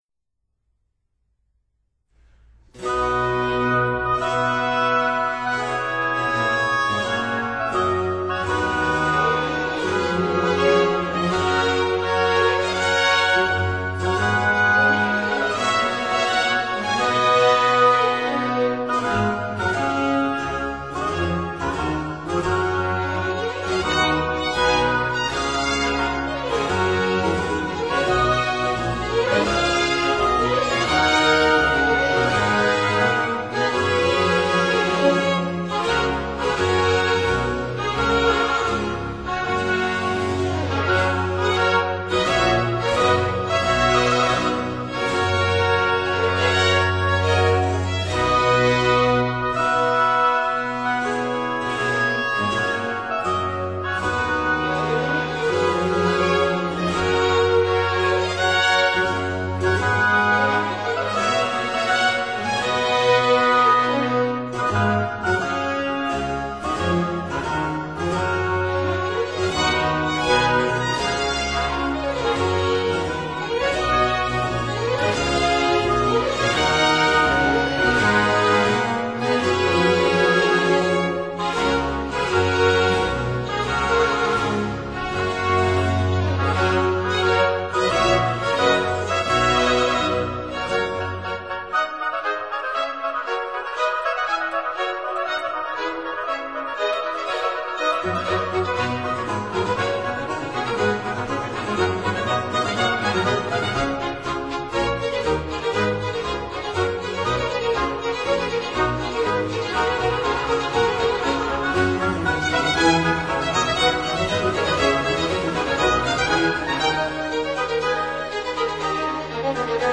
(Period Instruments)